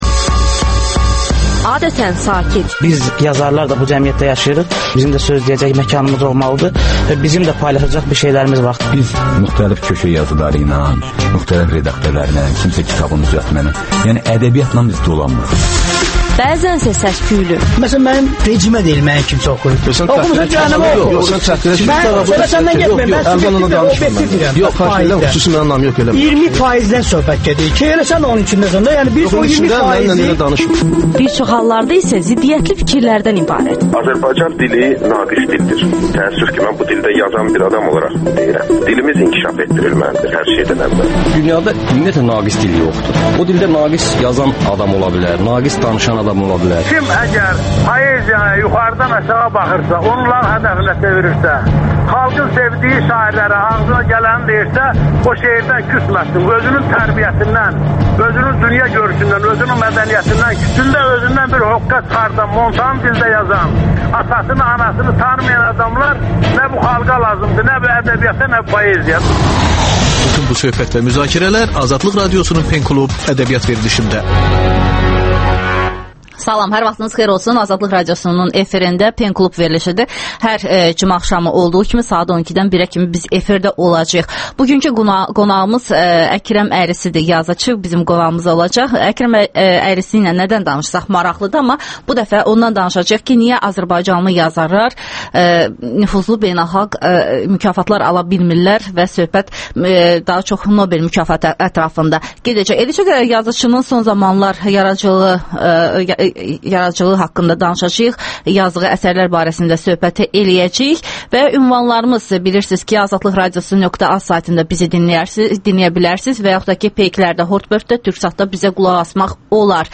Pen club - Azərbaycanda «Nobel» ala biləcək yazar varmı? Əkrəm Əylisli ilə söhbət
Ədəbiyyat verilişi